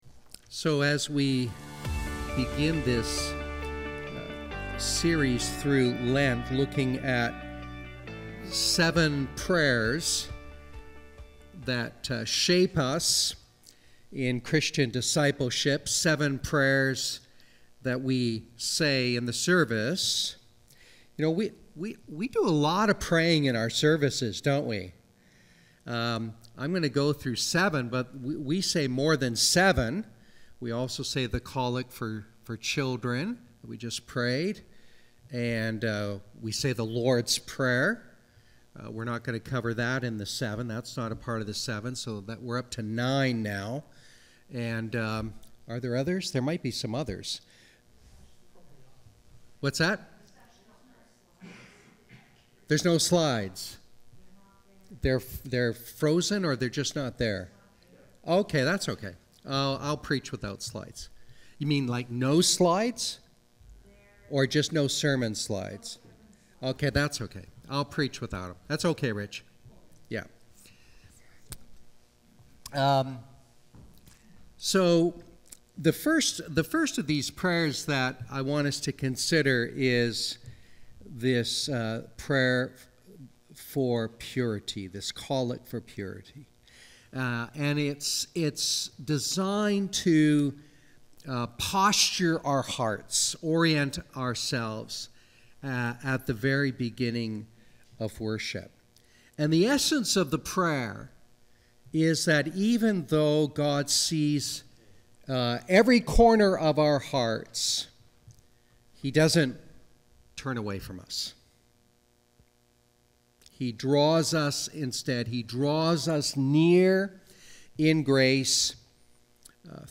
Sermons | Church of the Ascension